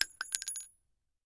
Bullet Shell Sounds
rifle_generic_8.ogg